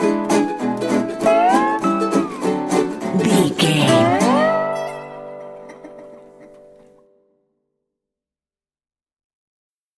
Ionian/Major
electric guitar
acoustic guitar
drums
ukulele
slack key guitar